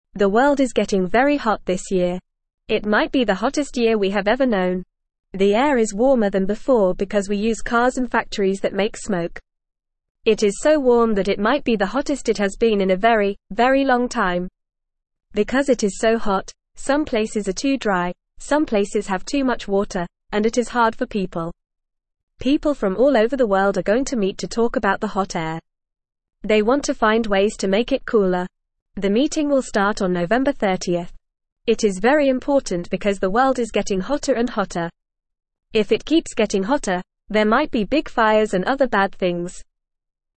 English-Newsroom-Beginner-FAST-Reading-The-World-Is-Getting-Very-Hot-This-Year.mp3